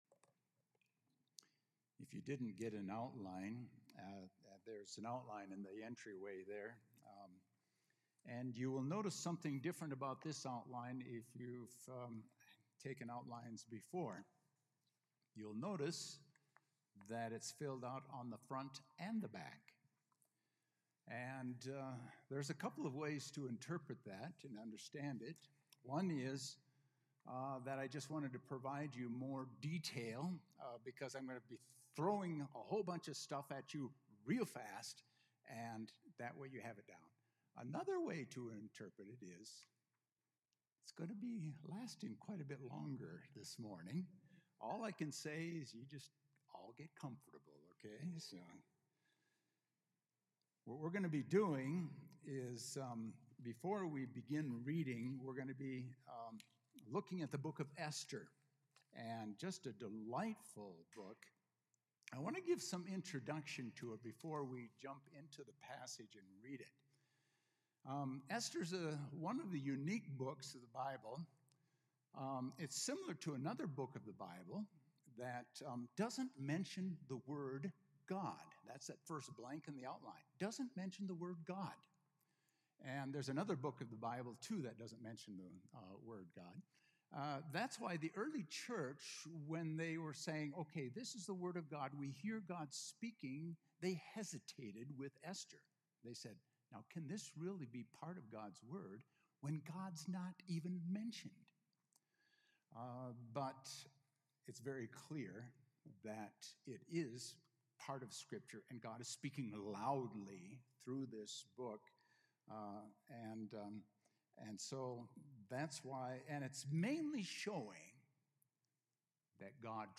A message from the series "Esther."